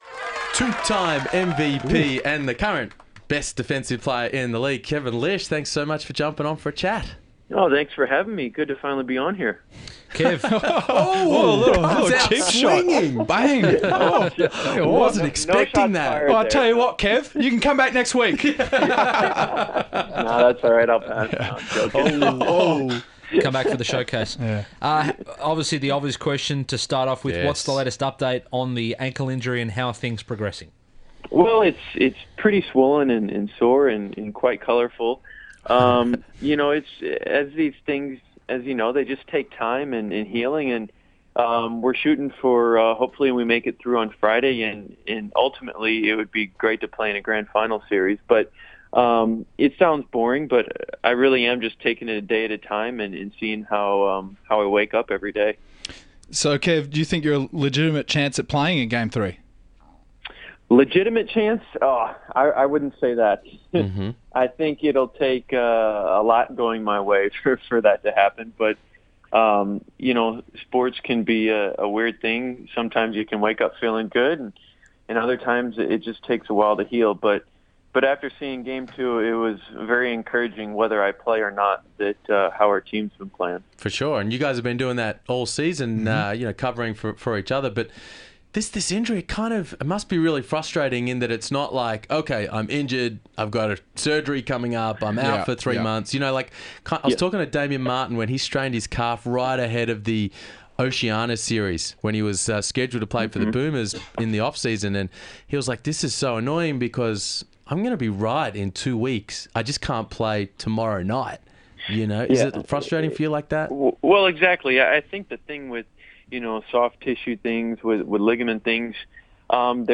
Kevin Lisch Interview